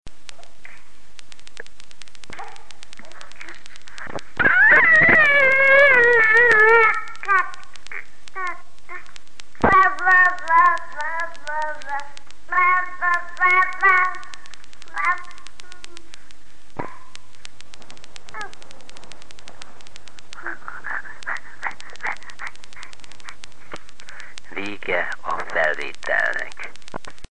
Ezután nagyjából kivehető, hogy az archív felvétel egy férfihangot, és gyereksírást, gügyögést tartalmaz.
A felvételen az apró sercegések mellett egy nagyjából ütemes "elektromos kisülésre emlékeztető", magas kattogó hang is hallható, ami a felvevő készülék érintkezéshibájából ered.